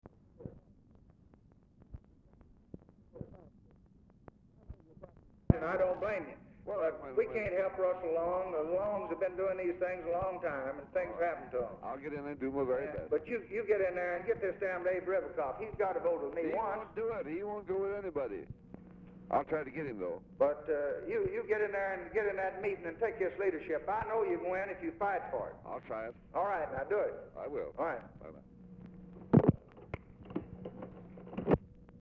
Telephone conversation # 1490, sound recording, LBJ and CLINTON ANDERSON, 1/23/1964, 1:05PM | Discover LBJ
Format Dictation belt
Location Of Speaker 1 Oval Office or unknown location
Specific Item Type Telephone conversation Subject Congressional Relations Legislation Taxes